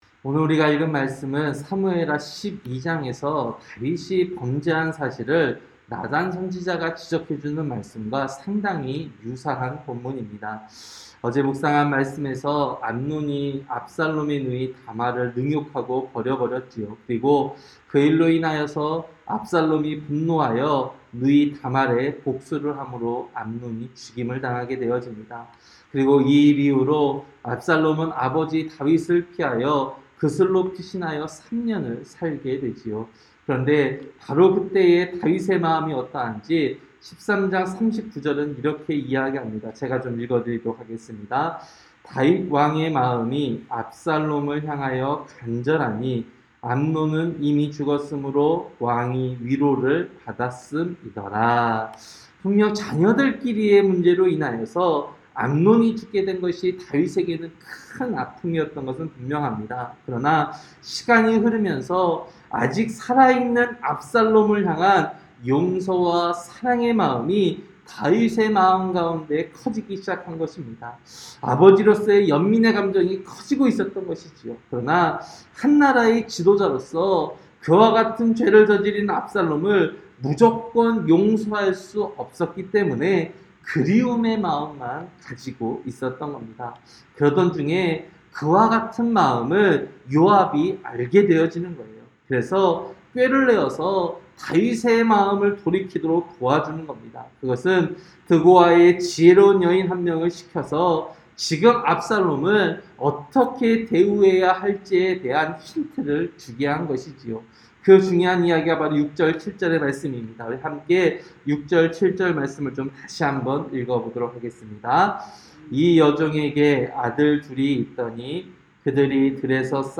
새벽설교-사무엘하 14장